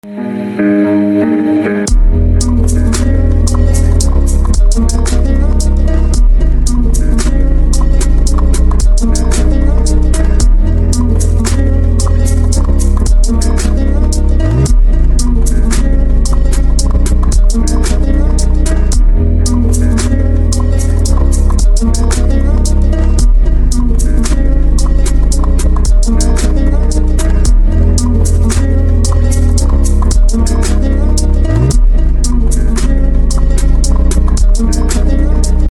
• Качество: 192, Stereo
гитара
восточные мотивы
атмосферные
спокойные
Trap
струнные
инструментальные
Chill Trap